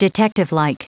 Prononciation du mot detectivelike en anglais (fichier audio)
Prononciation du mot : detectivelike
detectivelike.wav